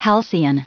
Prononciation du mot halcyon en anglais (fichier audio)
Prononciation du mot : halcyon